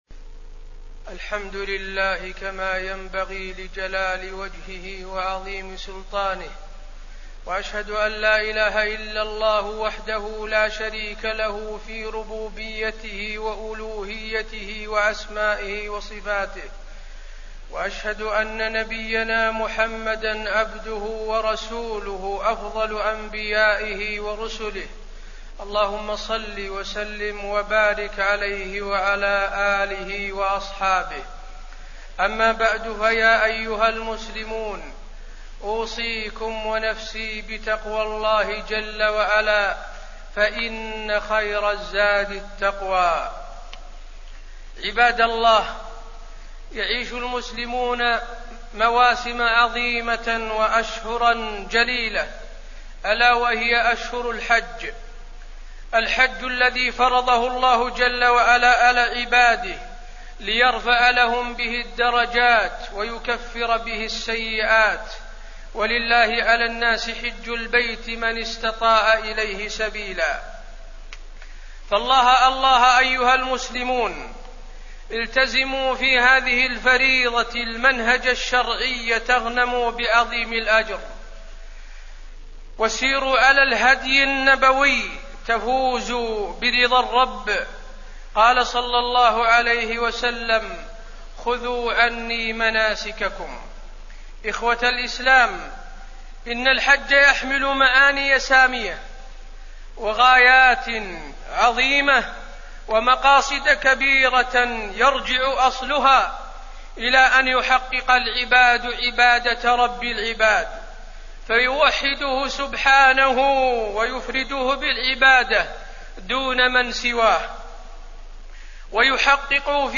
تاريخ النشر ٢٥ ذو القعدة ١٤٣٠ هـ المكان: المسجد النبوي الشيخ: فضيلة الشيخ د. حسين بن عبدالعزيز آل الشيخ فضيلة الشيخ د. حسين بن عبدالعزيز آل الشيخ آداب الحج